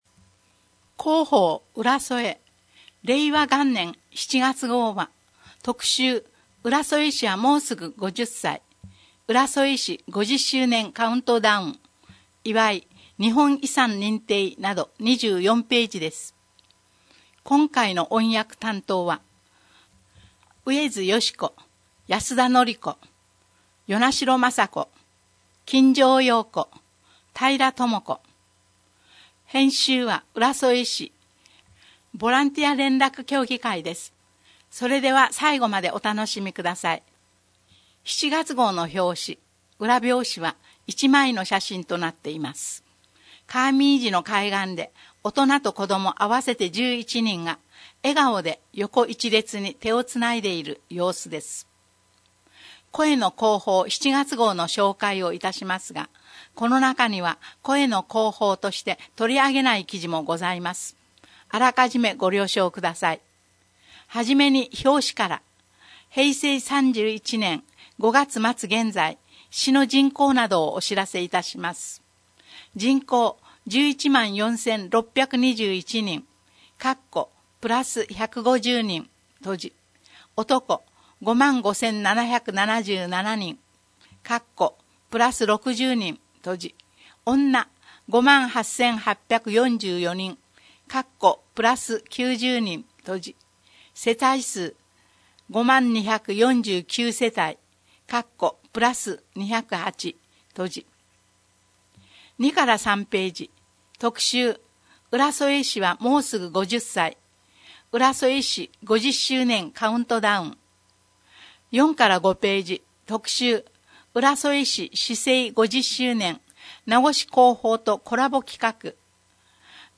「広報うらそえ」を朗読したものを音声データ化しています。